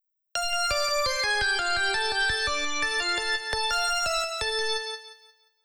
こちらがサビを耳コピしたデモの冒頭です。